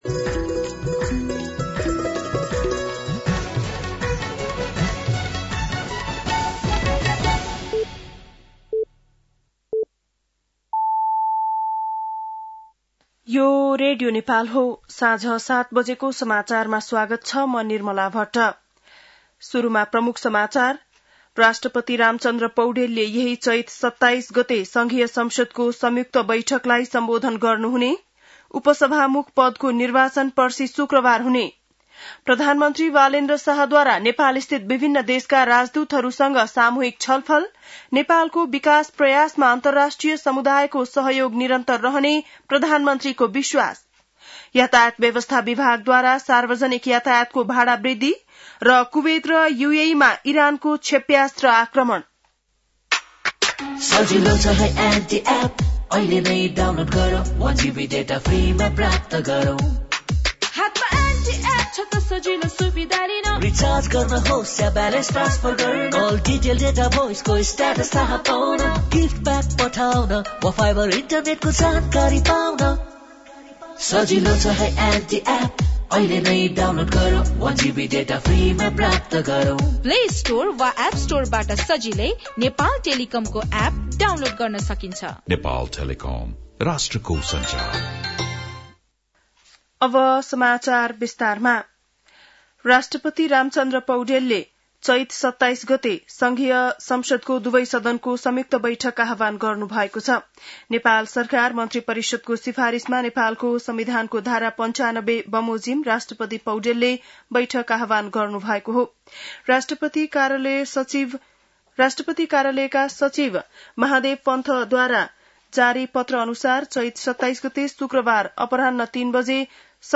बेलुकी ७ बजेको नेपाली समाचार : २५ चैत , २०८२